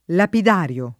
lapidario [ lapid # r L o ]